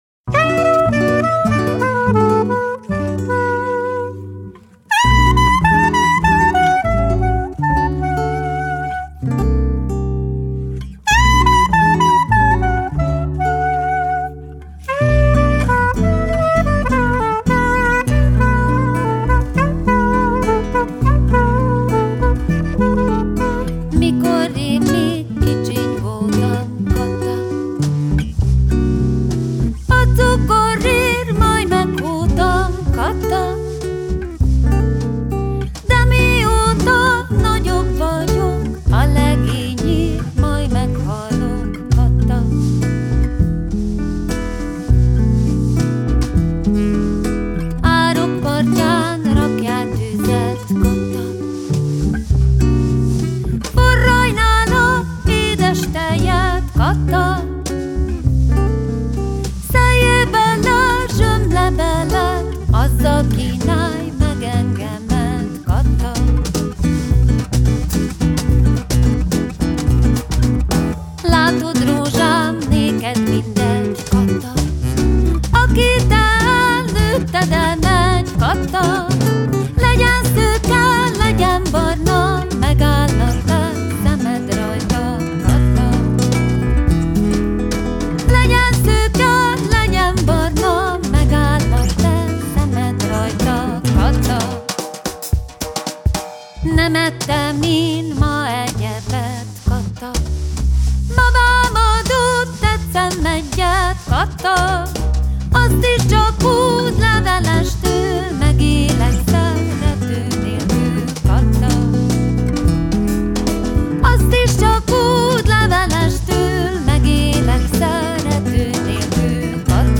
népdalénekesnő